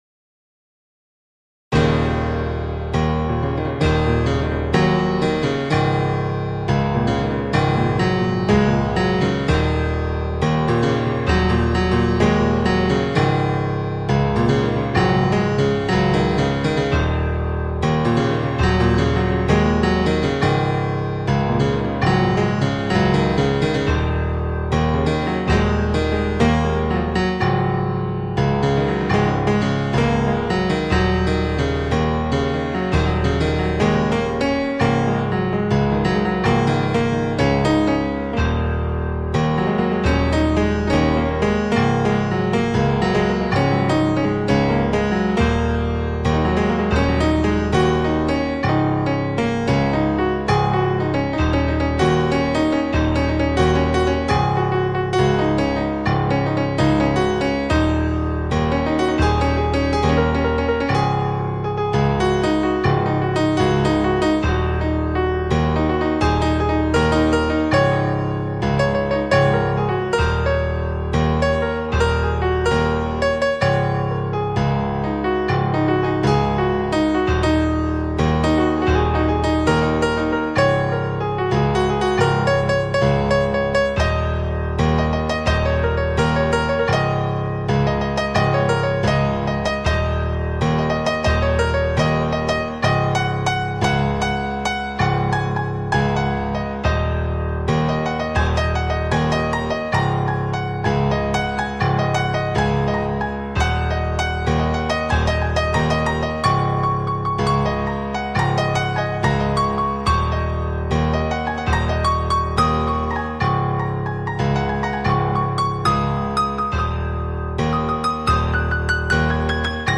Piano Keys